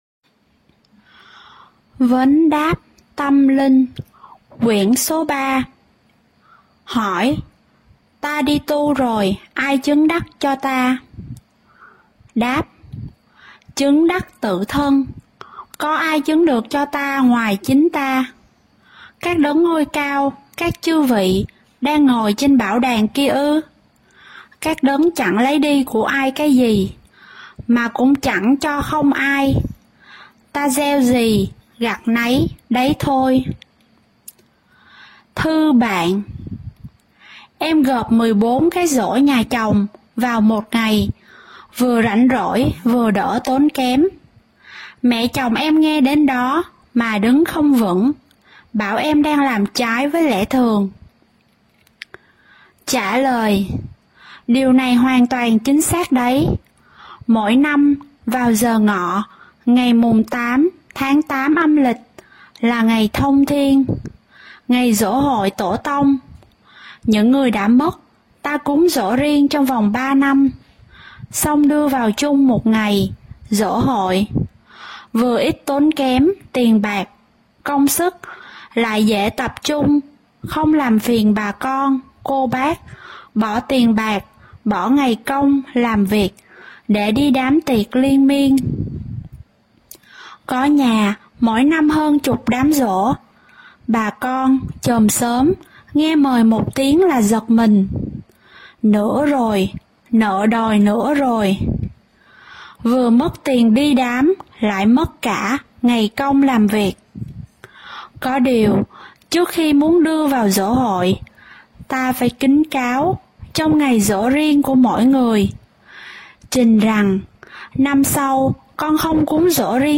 1044. Sách đọc - Vấn đáp tâm linh - Quyển ba - Vị Lai Pháp